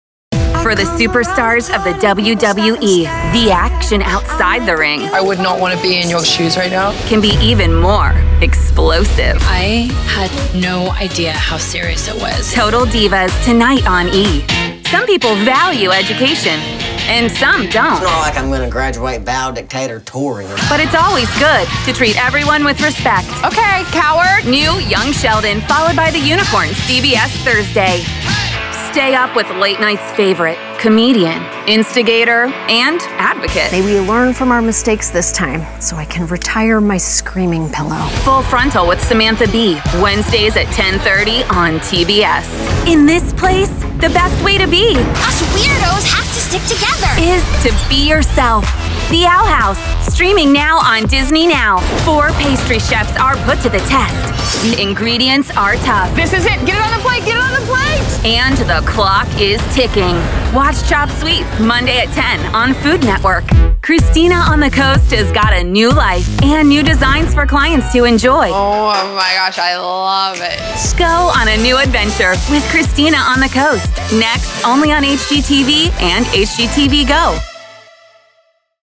Broadcast quality Source Connect home studio.
Teenager, Adult, Young Adult